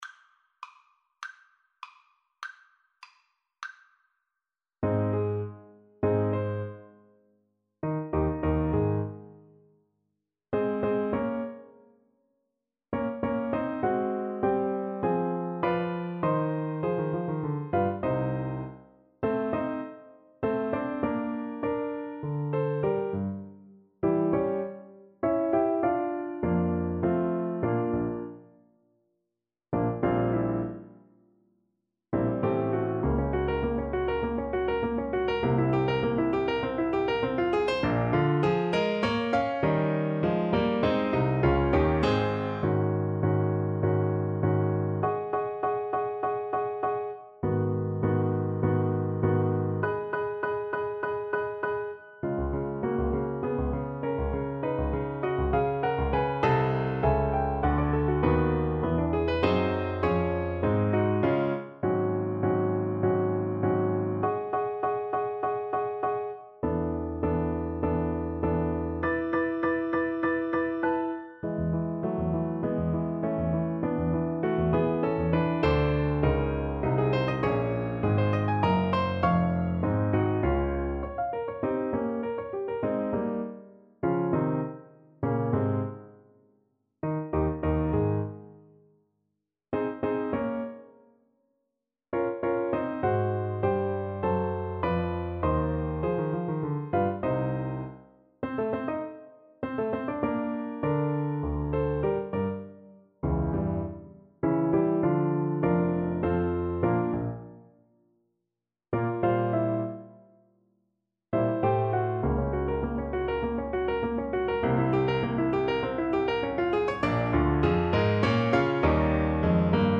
~ = 200 Allegro Animato (View more music marked Allegro)
Classical (View more Classical Clarinet Music)